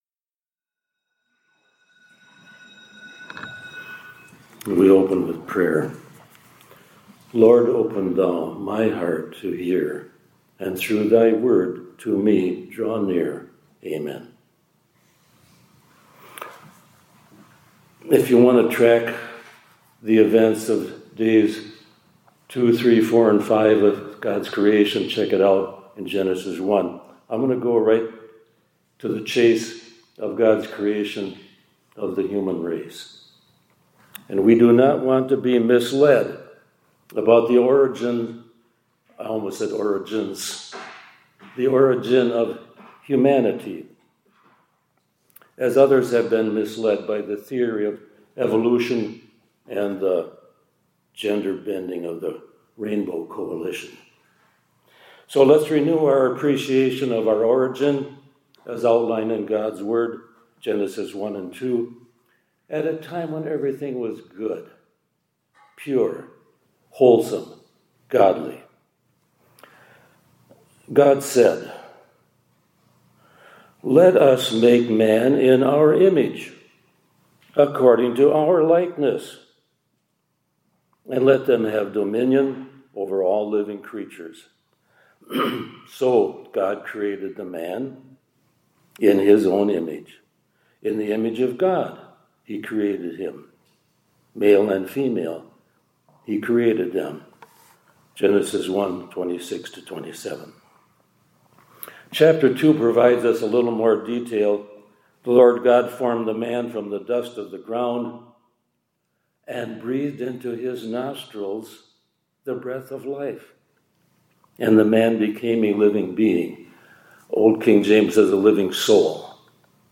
2025-11-06 ILC Chapel — God Recreates His Image In…